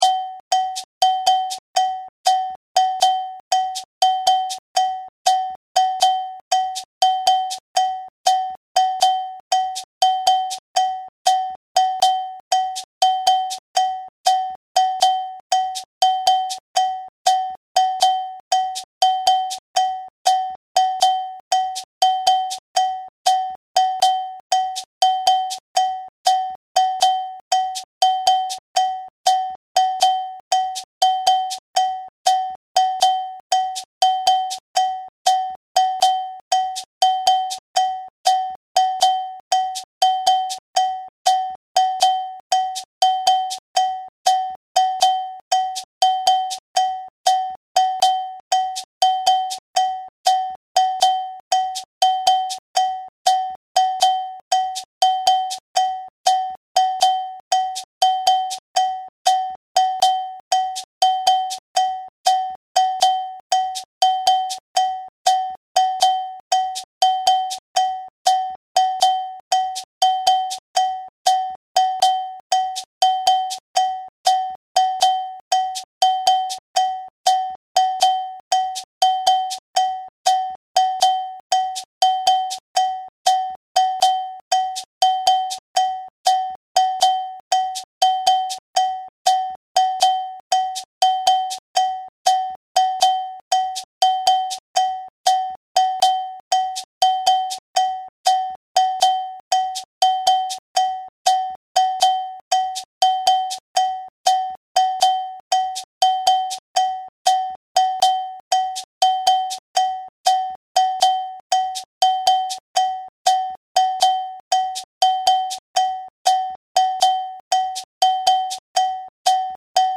12/8 SHORT BELL – An important bell pattern in traditional West African and Afro-Caribbean drum music.
12/8 Short Bell
audio (with shekeré)
128-Short-Bell-Slow-FREE.mp3